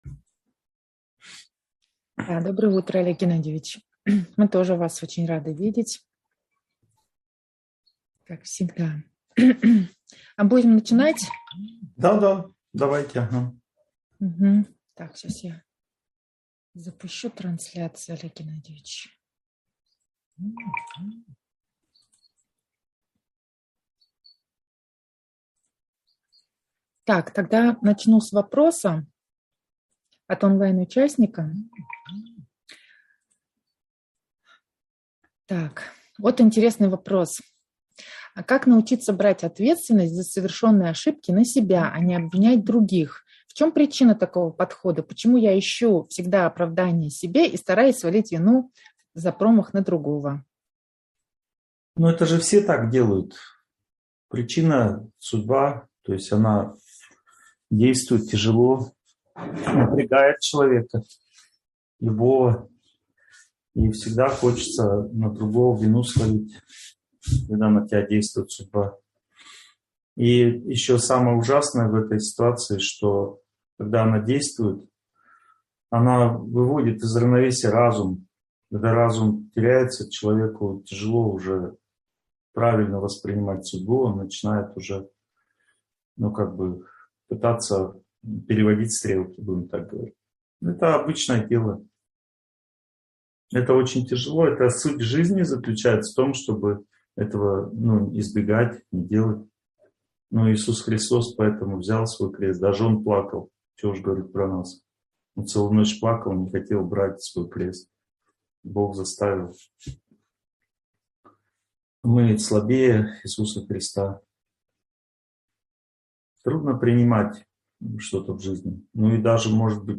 Ответственность и ее делегирование. Часть 2 (онлайн-семинар, 2022)